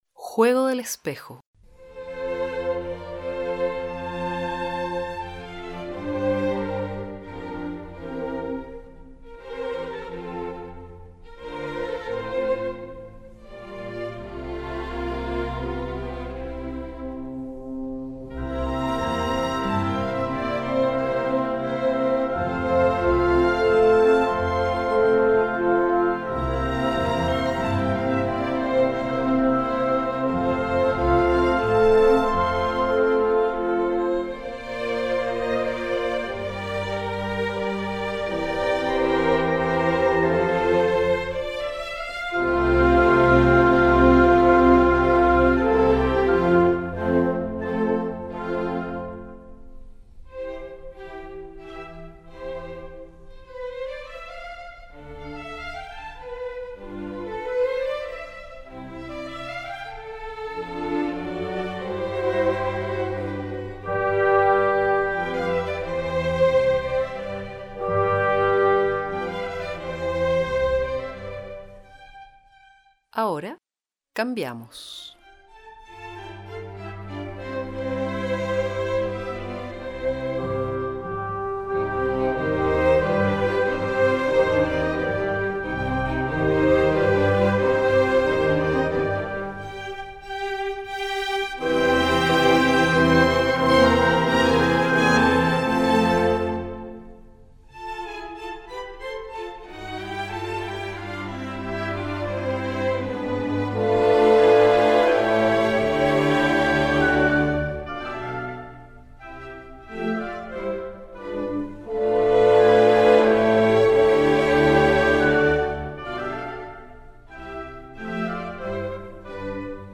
Melodía.